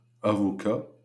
The phonemic contrast between front /a/ and back /ɑ/ is sometimes no longer maintained in Parisian French, which leads some researchers to reject the idea of two distinct phonemes.[28] However, the back [ɑ] is always maintained in Northern French, but only in final open syllables,[29] avocat (lawyer) [avokɑ]